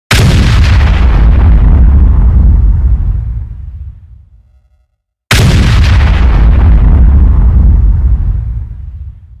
Tiếng Xe Tăng Bắn (MP3)